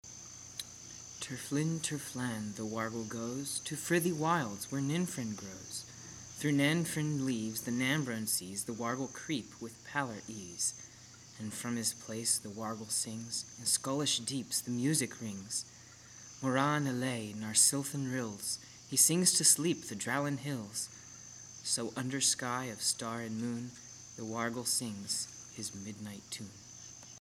I like the background effects.
I like the crickets.